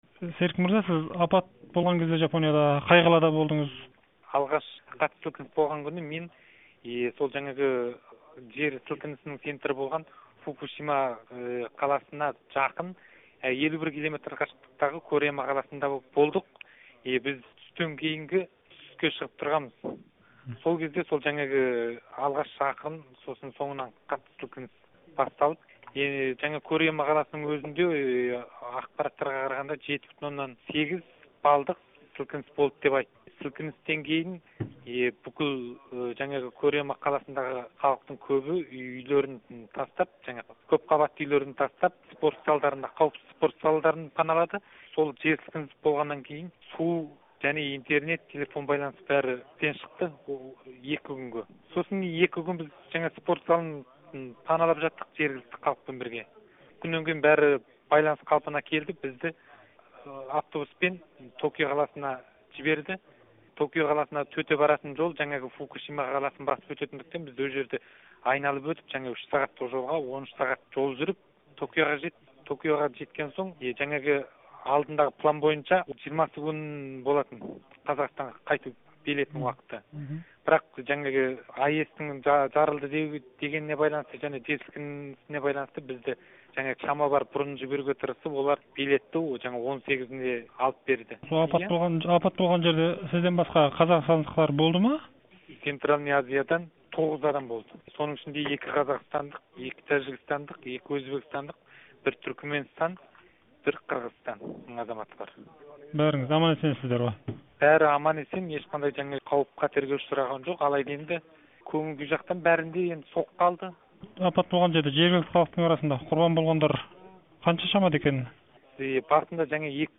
Жапониядан берген хабарын